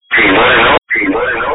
Improving a voice recording with heavy clipping
The original is actually a bit more clipped. I’ve exported it to wave with a gain of 22 dB (!).
The filter has been applied after re-importing.